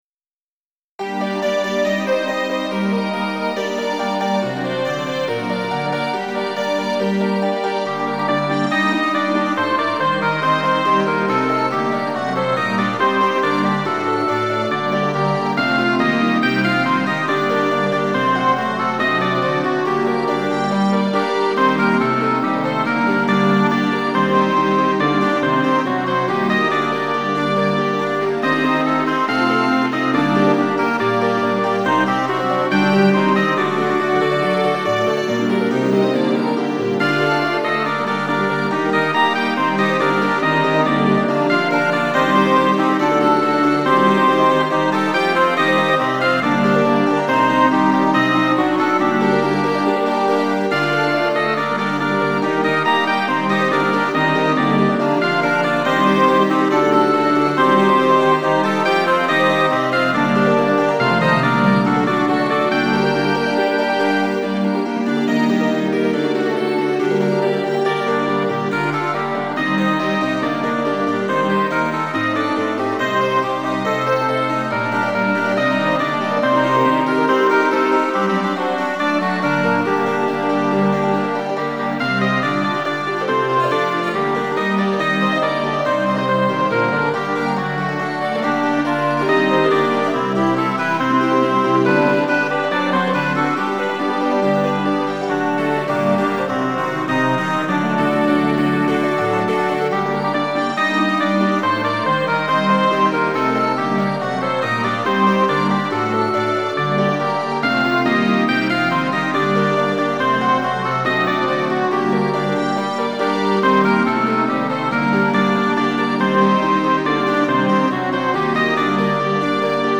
ト短調